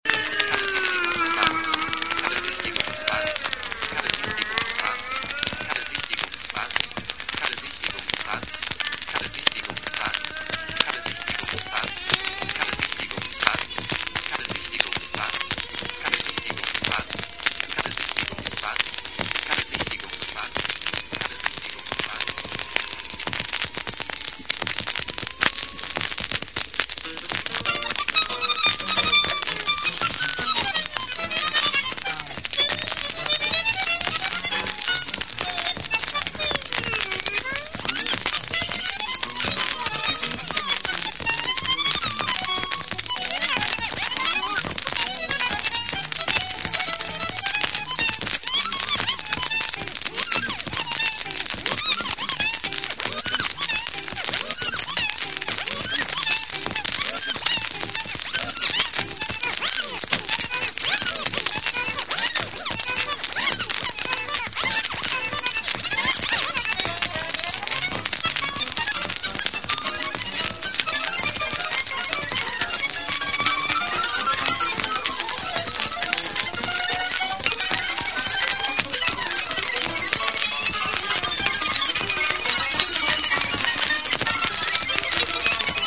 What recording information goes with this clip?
recorded live in Centro Cultural Oswald de Andrade,